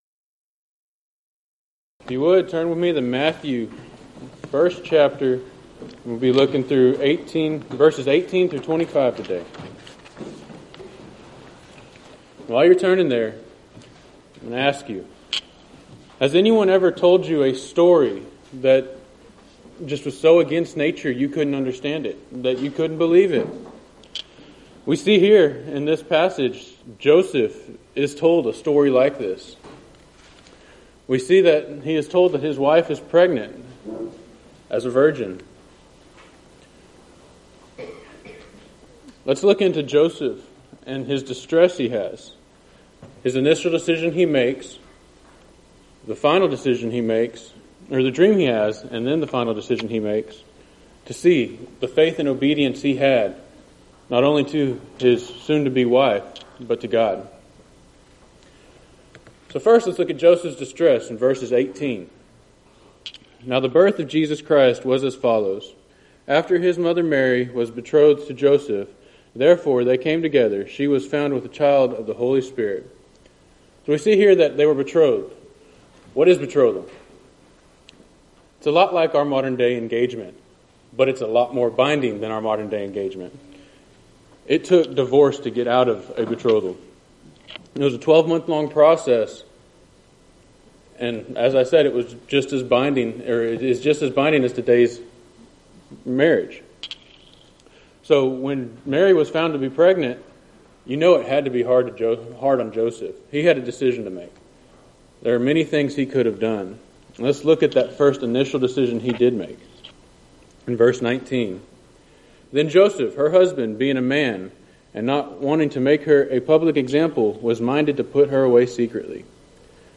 Event: 3rd Annual Southwest Spritual Growth Workshop